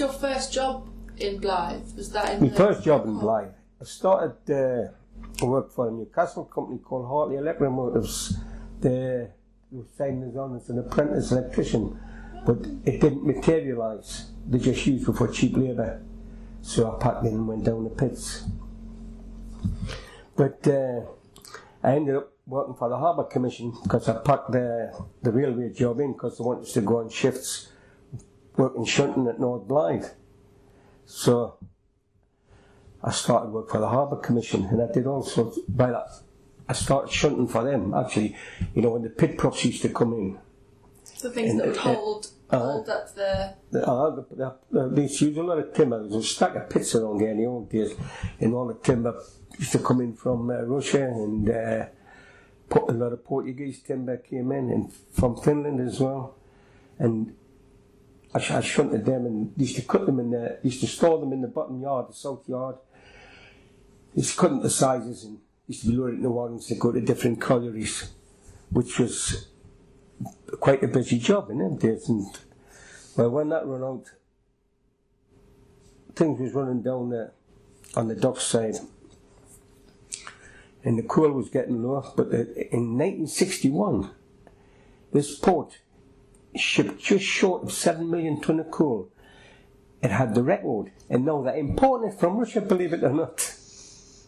These sound files are extracts (short, edited pieces) from longer oral history interviews preserved by Northumberland Archives.